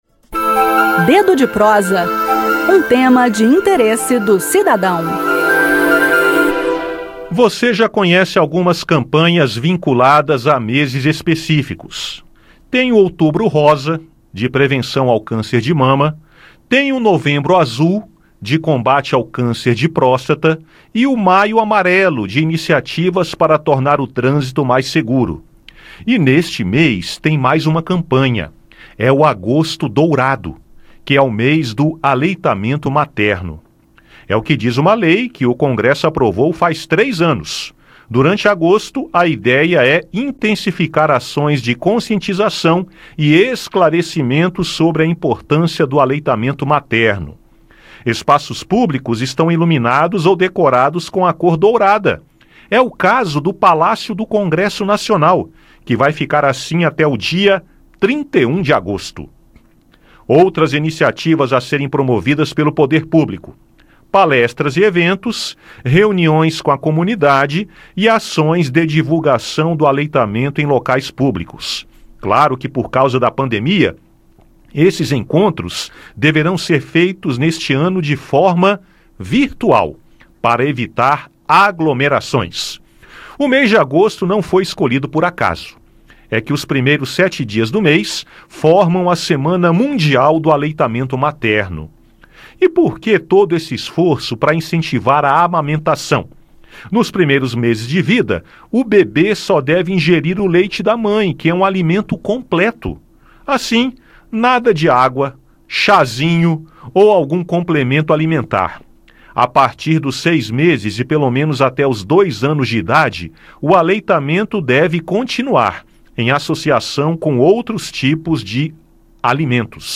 Ouça o bate-papo